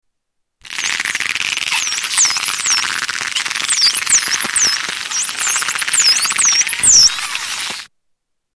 Spotted dolphin echolocation
Category: Animals/Nature   Right: Personal